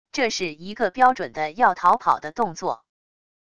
这是一个标准的要逃跑的动作wav音频